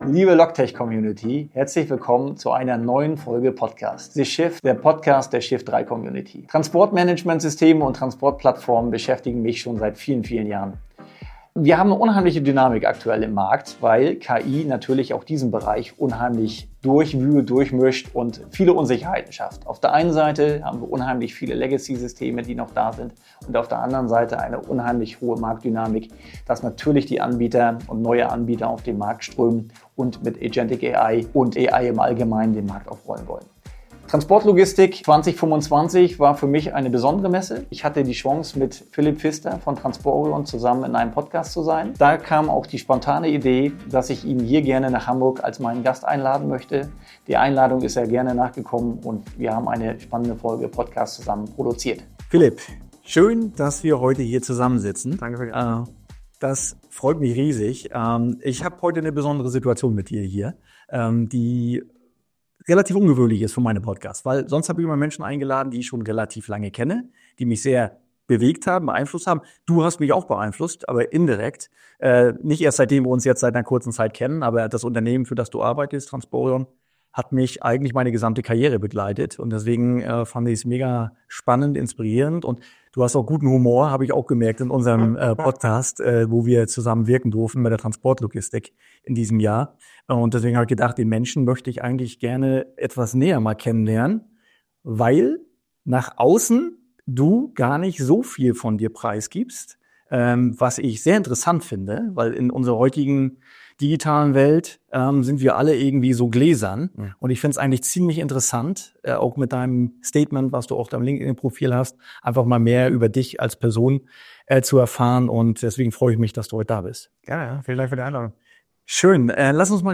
Dieses Mal im Gespräch